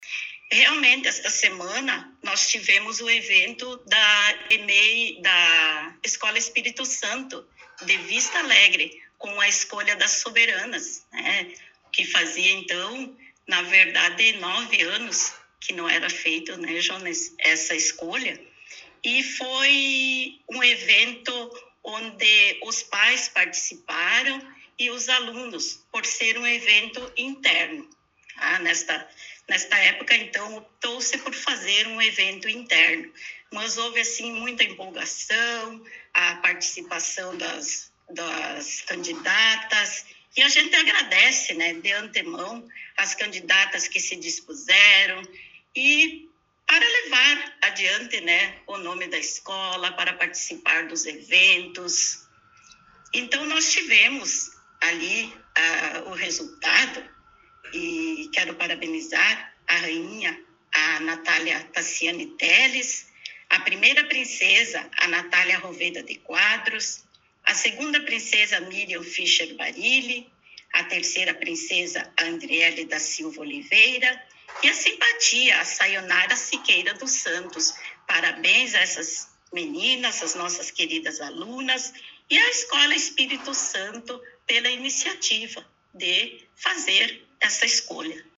Secretária Municipal de Educação, Jorgina de Quadros, concedeu entrevista